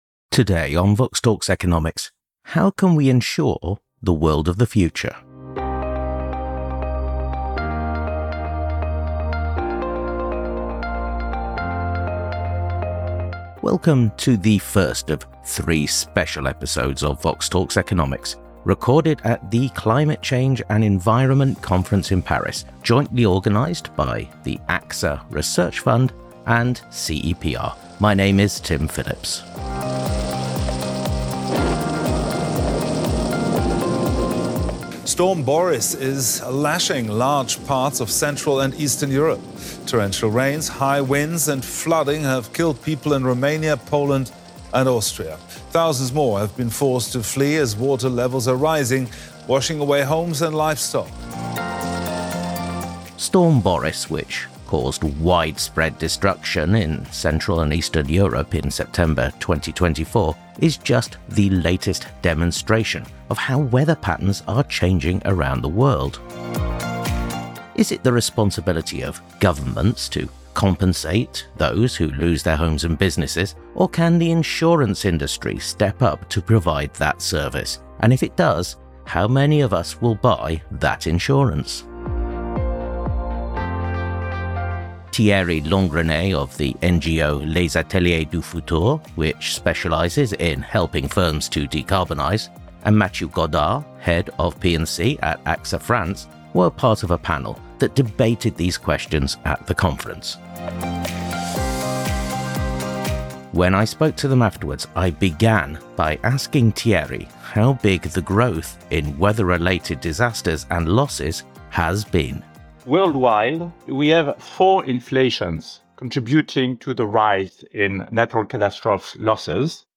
The first of three special episodes of VoxTalks Economics recorded at the Climate Change and the Environment Conference in Paris, jointly organised by the AXA Research Fund and CEPR.